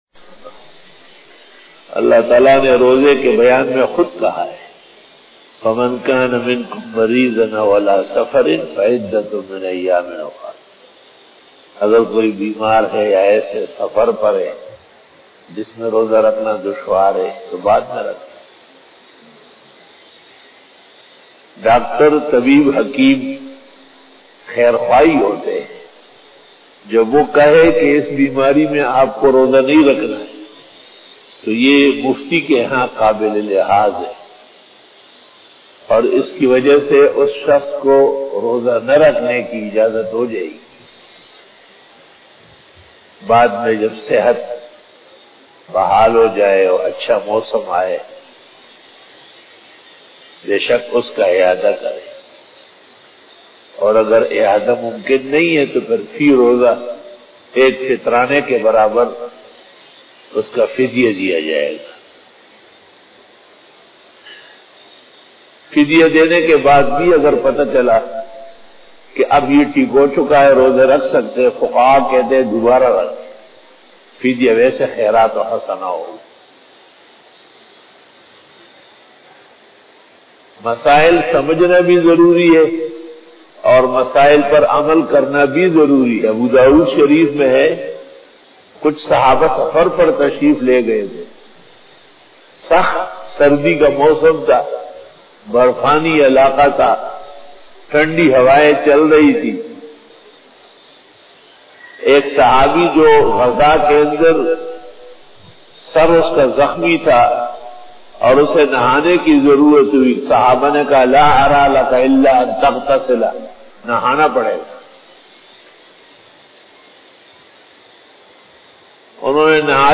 بیان بعد نماز فجر
After Fajar Byan